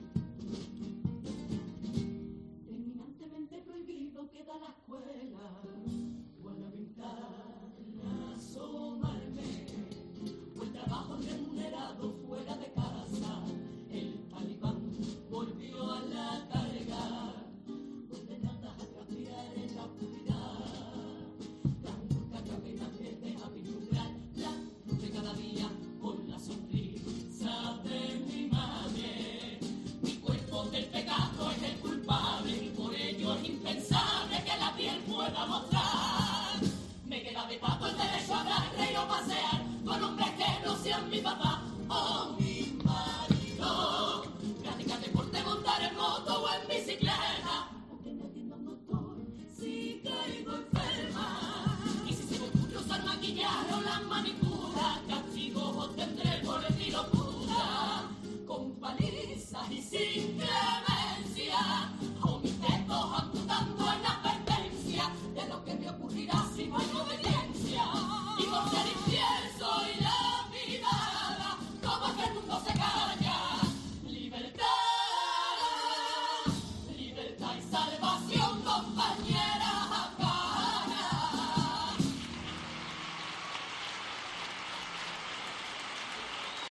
El pasodoble a las mujeres afganas de la comparsa 'Las musas'
en la tercera sesión de cuartos de final del Concurso de Agrupaciones del Carnaval de Cádiz